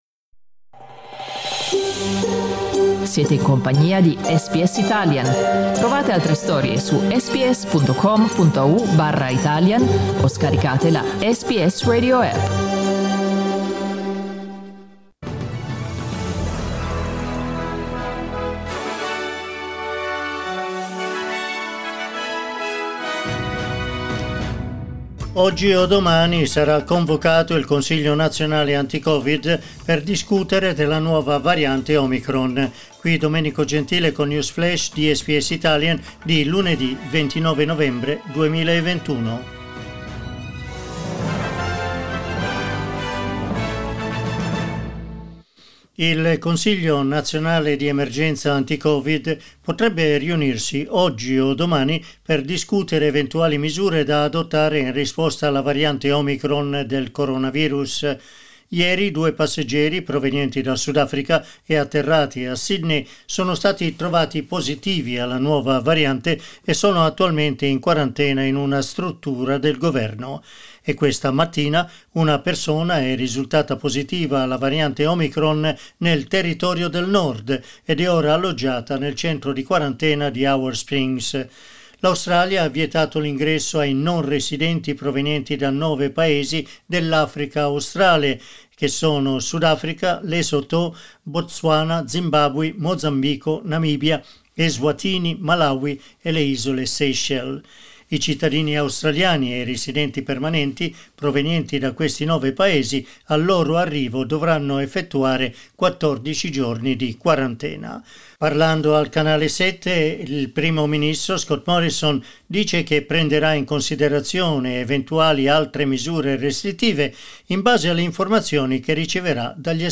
News flash lunedì 29 novembre 2021